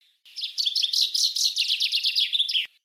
Большинство песен зяблика устроено так: вначале идет одна или несколько разных трелей (серий одинаковых нот), а в конце звучит заключительная громкая фраза — «росчерк».
Представьте себя Питером Марлером, послушайте записи песен шести зябликов и подберите для каждой из них трели и «росчерк» из предложенных схем.
chaffinch4.mp3